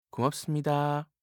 알림음 8_고맙습니다5-남자.mp3